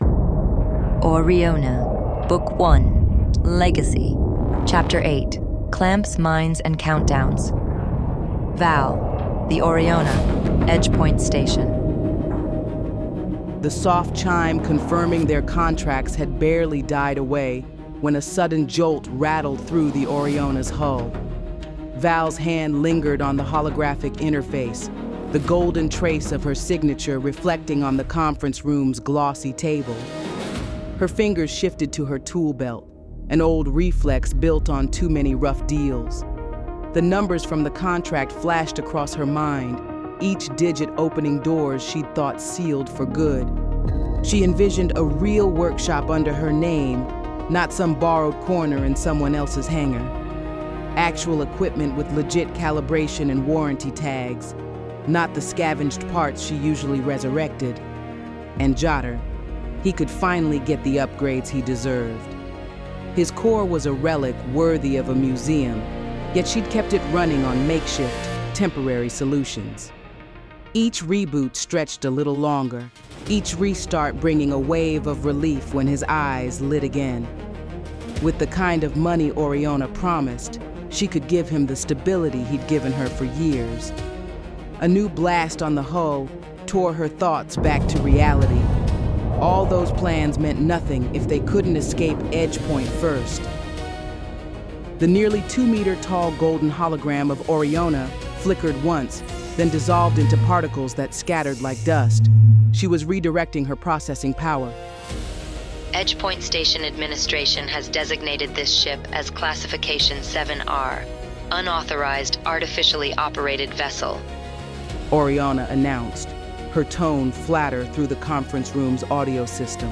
Quickly and easily listen to ORRIONA Gay Space Opera Cinematic Audiobook Series for free!